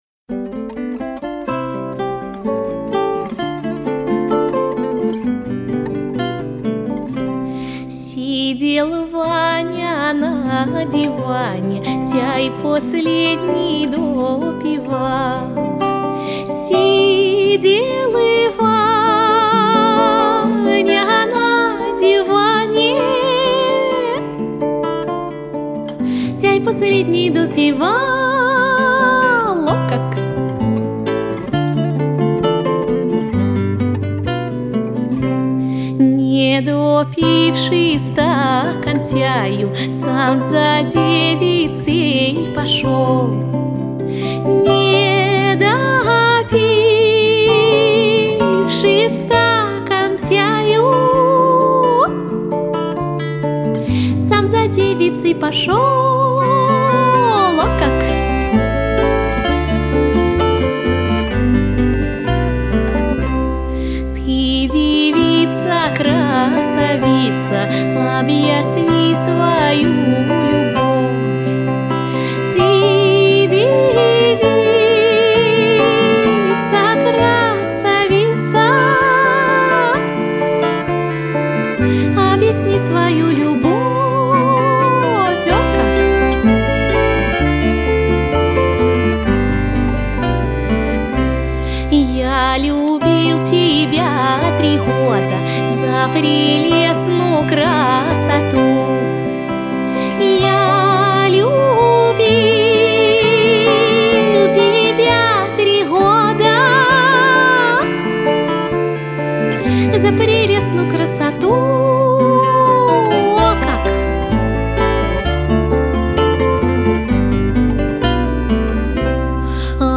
вот вам потяжелее и по старше)